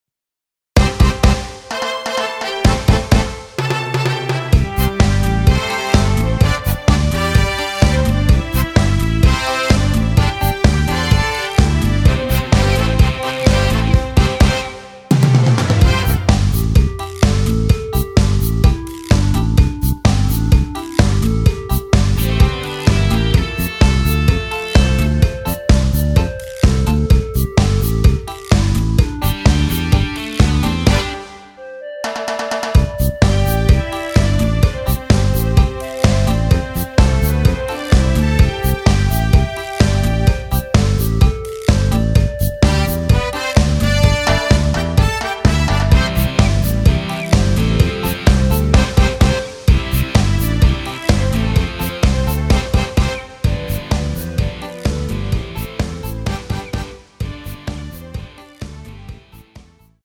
원키에서(-2)내린 멜로디 포함된 MR 입니다.
앞부분30초, 뒷부분30초씩 편집해서 올려 드리고 있습니다.
중간에 음이 끈어지고 다시 나오는 이유는